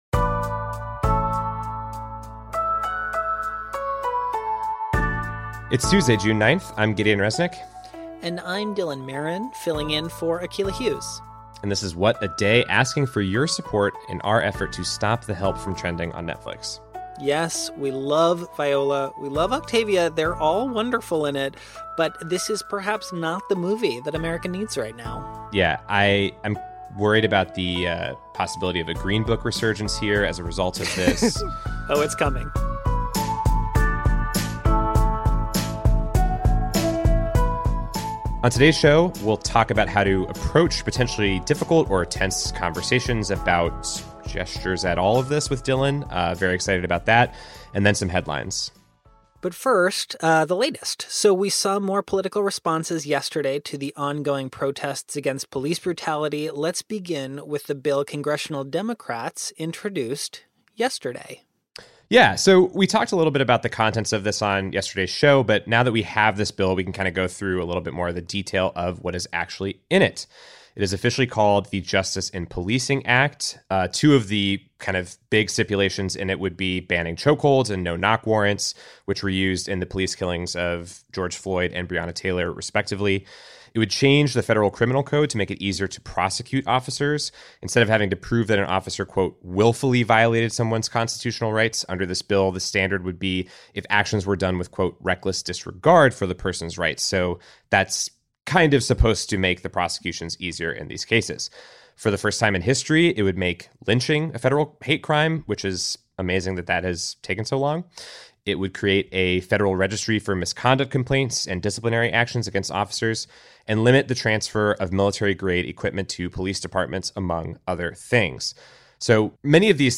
Guest-host Dylan Marron fills in for Akilah Hughes.